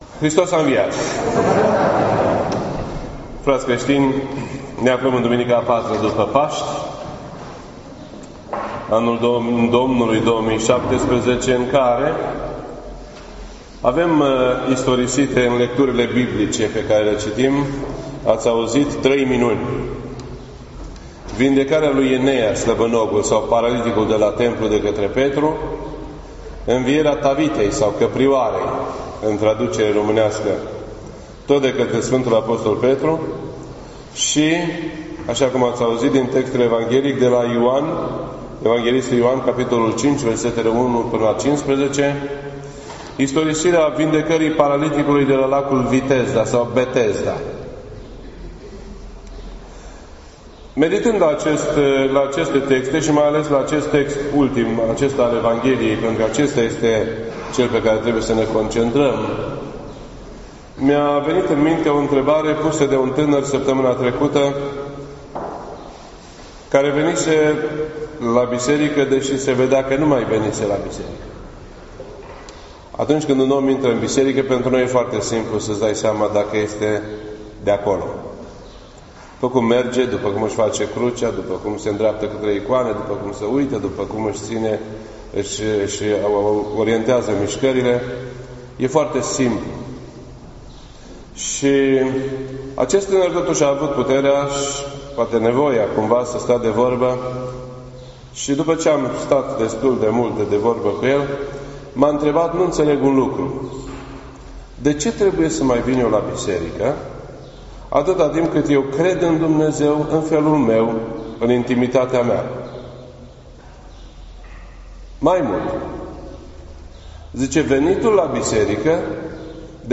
This entry was posted on Sunday, May 7th, 2017 at 6:59 PM and is filed under Predici ortodoxe in format audio.